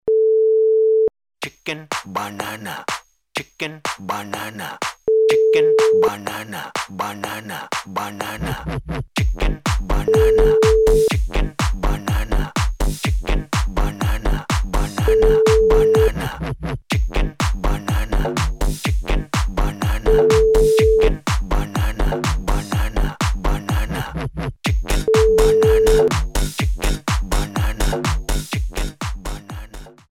Easy Listening Dance